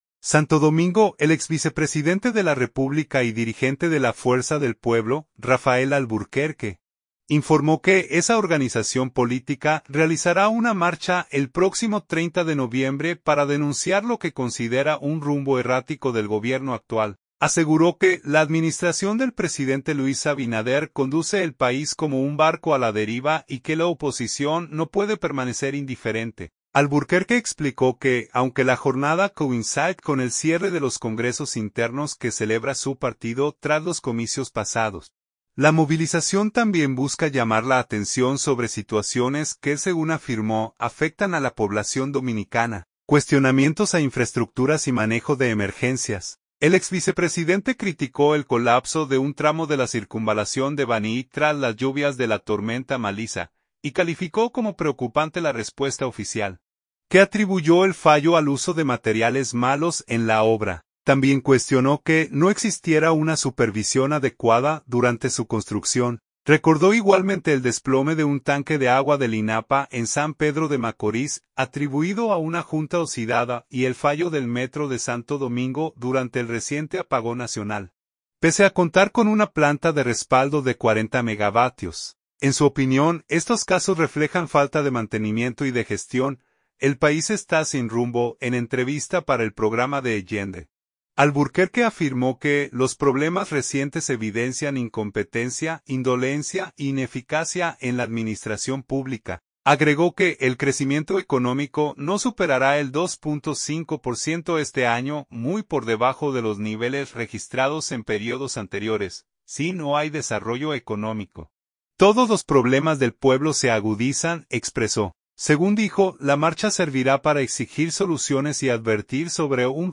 En entrevista para el programa D’Agenda, Alburquerque afirmó que los problemas recientes evidencian “incompetencia, indolencia e ineficacia” en la administración pública.